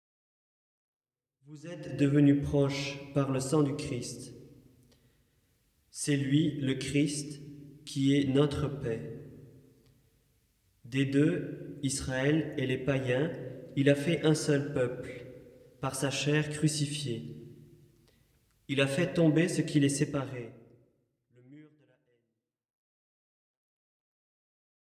08. (01:00) Lecture : Ep 2, 13-18 (+0.99 EUR)